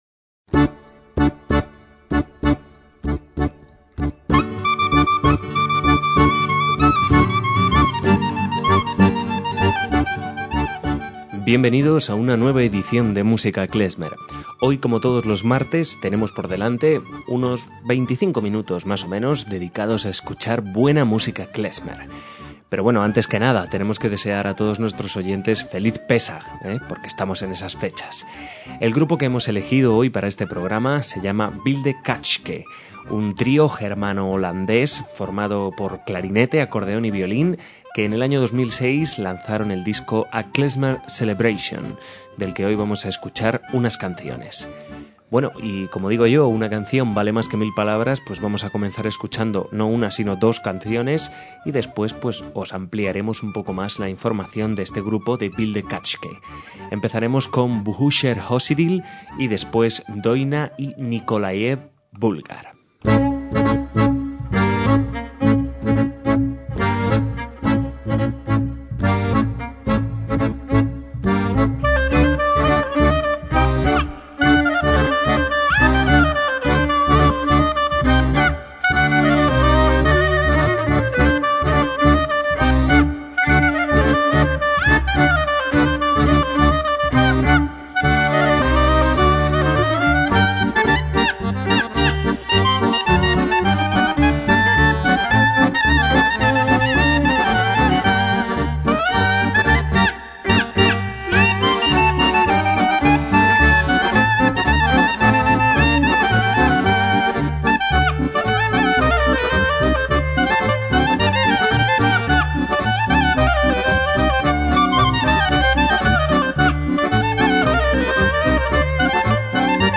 MÚSICA KLEZMER - El trío de música klezmer holandés
clarinete
percusiones
violín